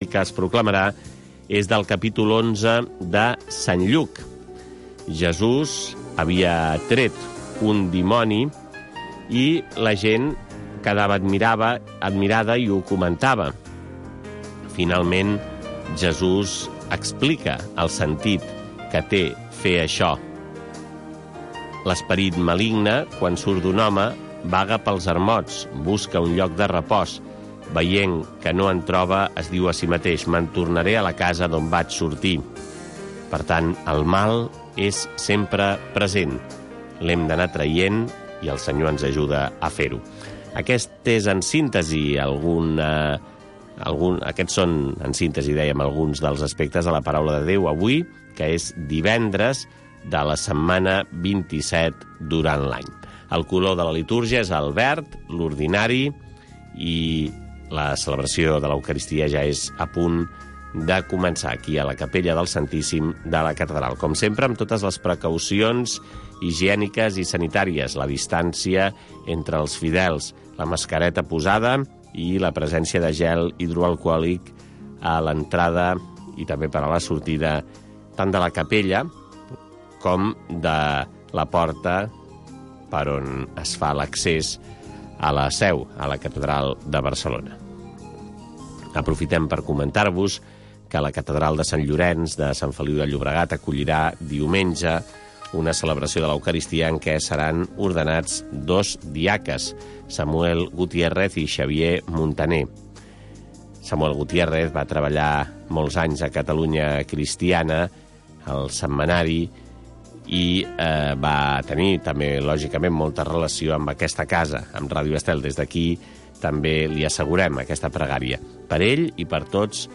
Cada dia pots seguir la Missa en directe amb Ràdio Estel.